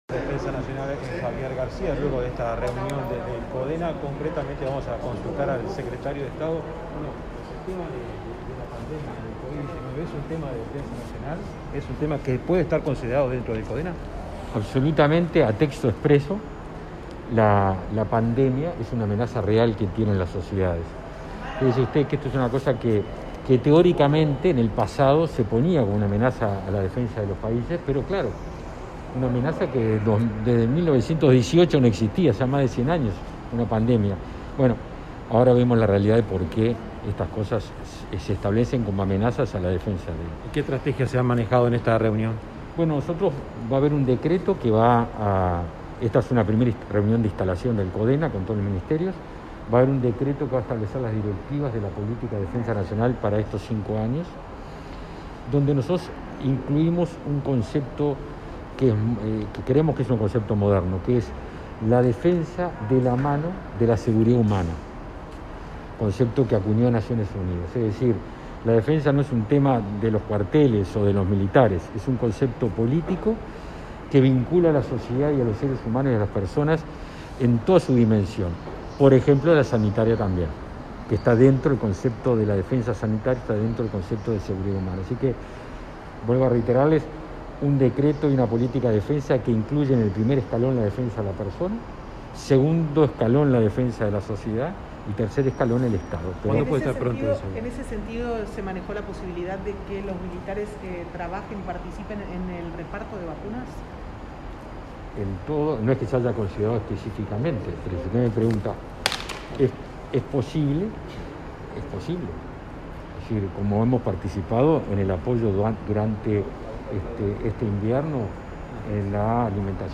Declaraciones de Javier García tras la primera reunión de Consejo de Defensa Nacional